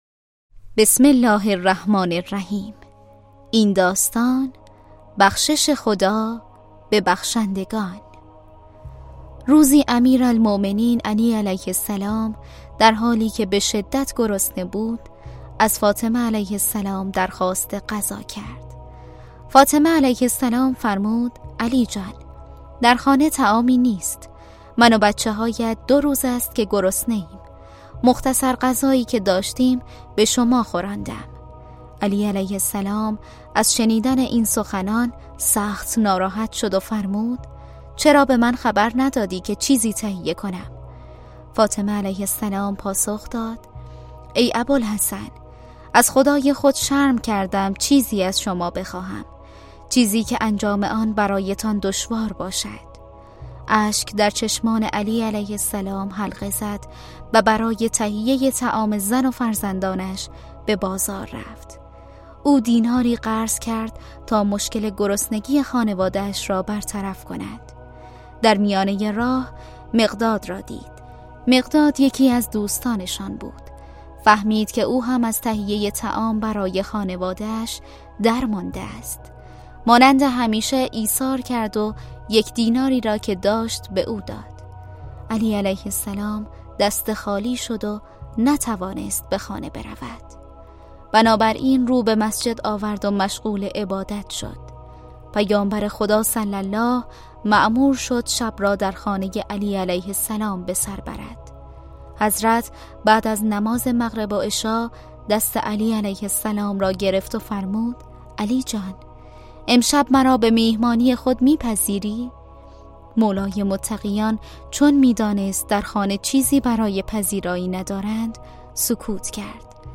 کتاب صوتی مهربانو